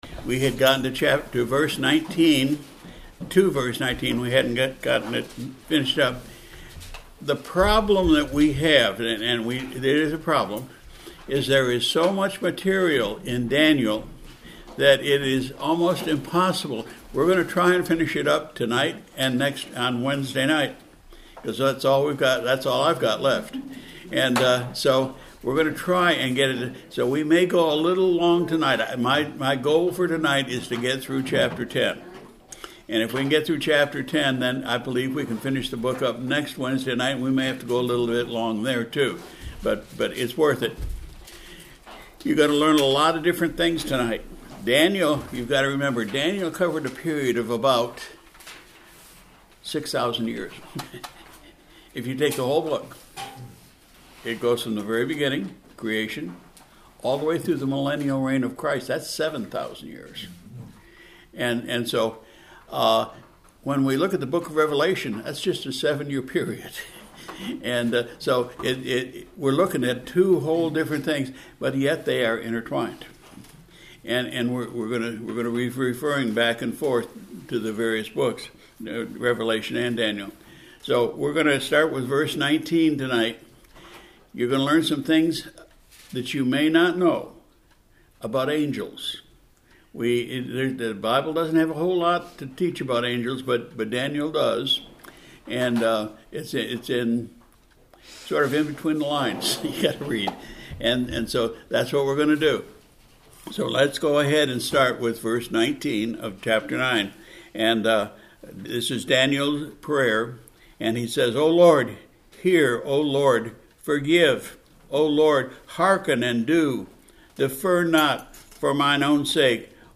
Sunday, March 23, 2025 – PM Service – Daniel 9:19-27 & 10:1-21